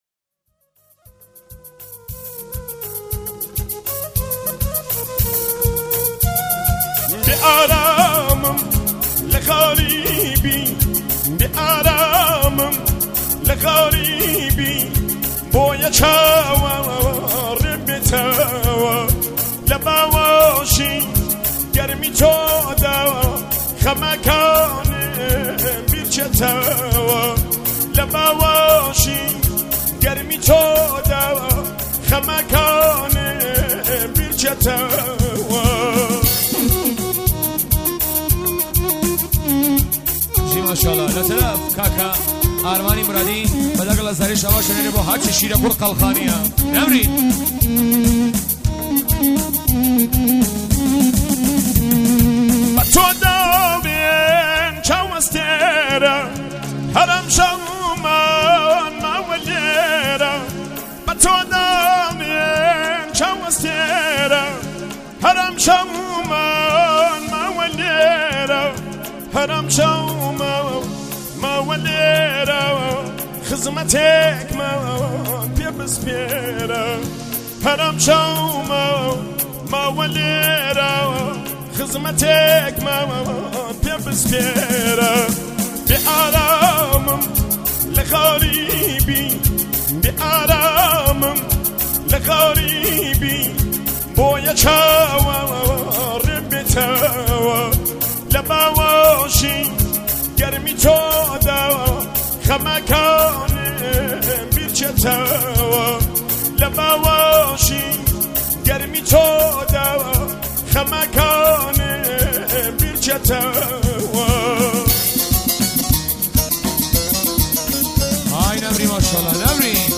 پرطرفدارترین اهنگ کردی شاد جدید و قدیمی